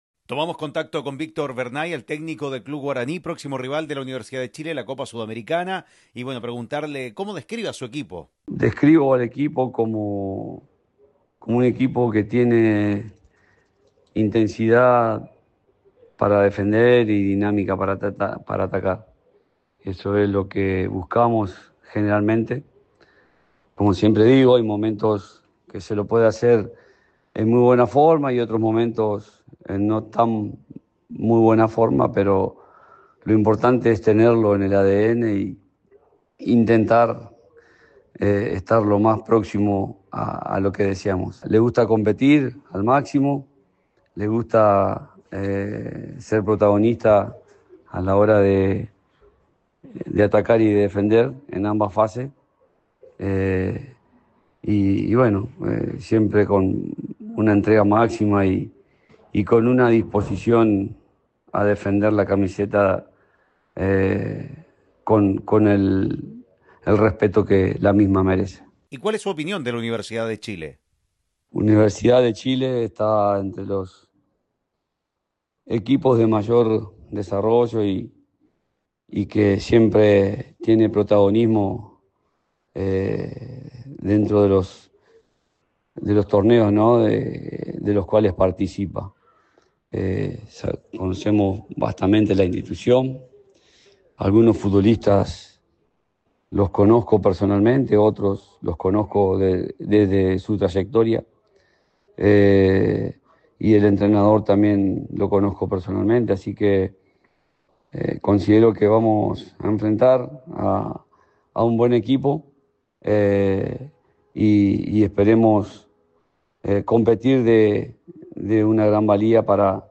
en diálogo con ADN Deportes